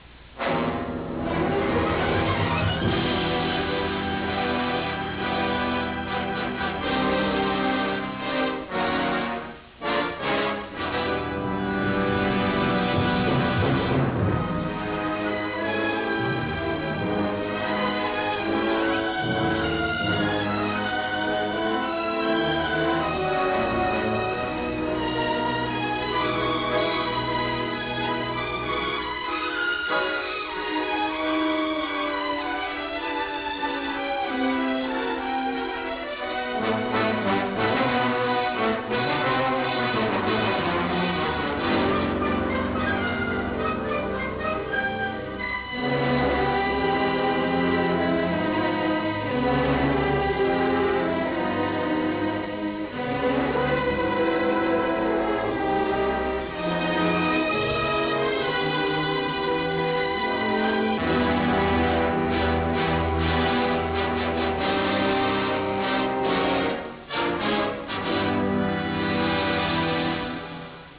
martellante, ossessiva musica
Track Music